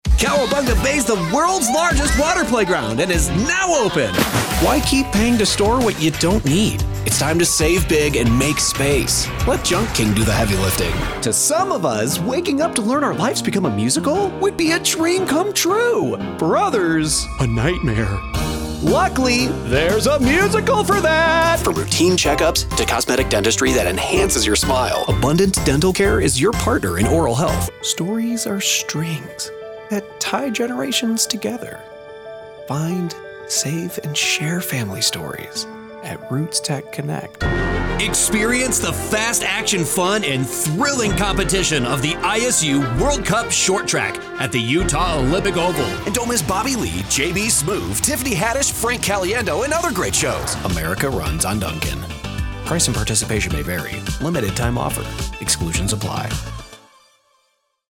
A youthful, millennial voice artist here to get your story out there!
Commercial Demo
Young Adult
If you're looking for that youthful, millennial sound I'm your guy!